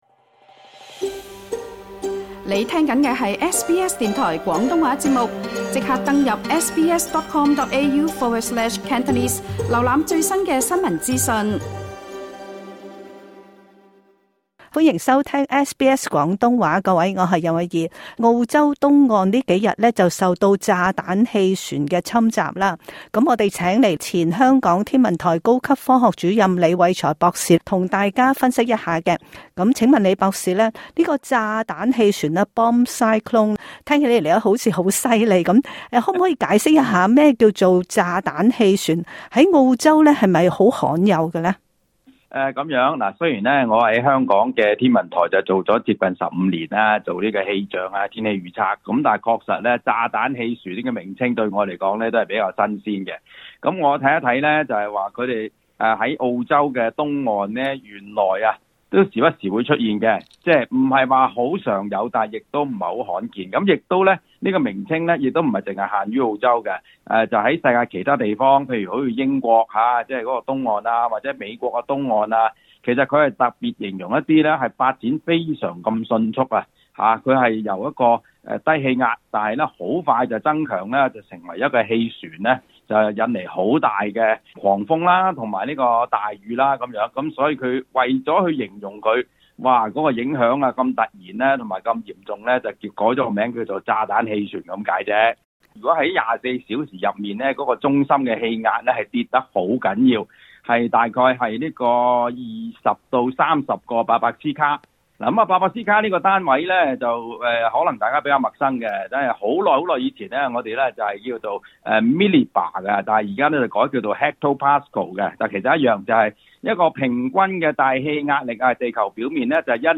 詳情請聽今集訪問。